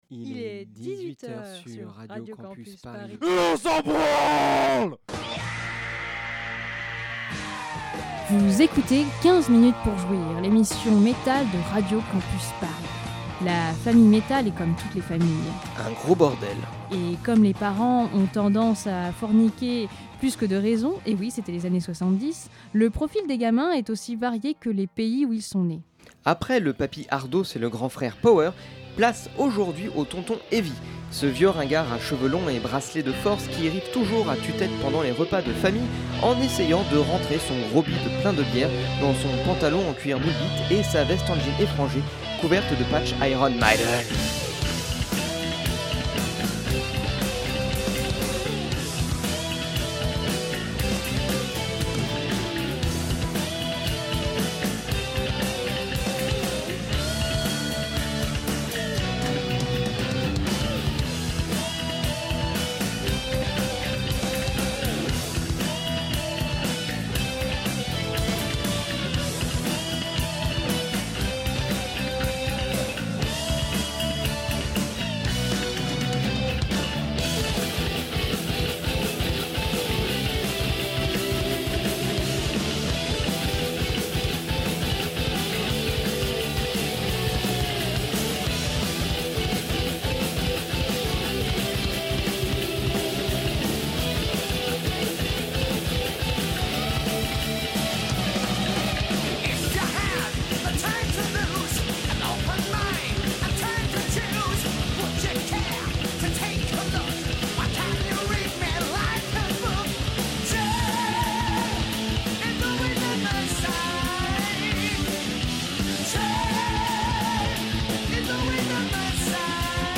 Celle qui double la cadence et pousse les potards au max pour le max de sensations, jusque dans le caleçon. Le heavy, c'est ce metal tellement classique qu'il en devient le parangon du genre. Cuir moulant et cheveux longs, on pousse un chant aigu et on suit la rythmique galopante pour un plaisir mélodique des plus mémorables.